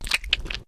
Compresses and normalizes vore sounds 2021-07-18 06:21:01 +00:00 13 KiB Raw History
insertion3.ogg